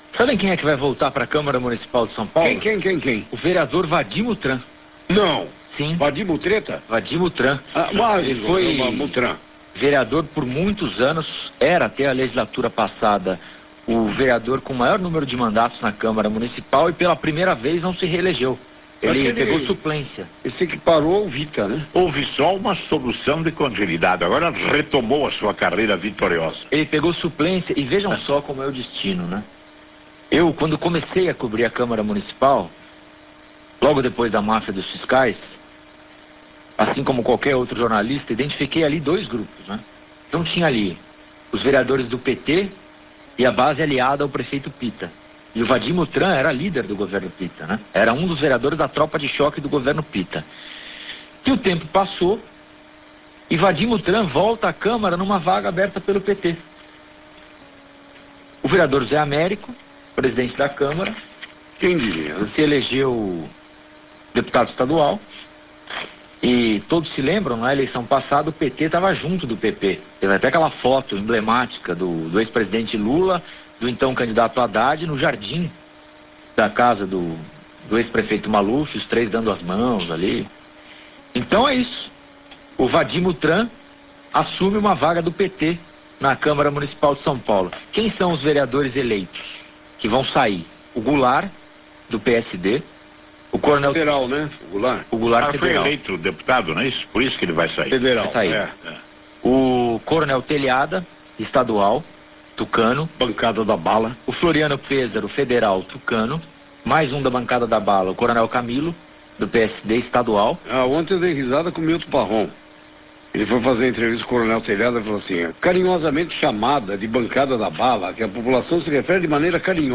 RÁDIO BANDEIRANTES AM/SP- Âncoras comentam sobre vereadores eleitos em SP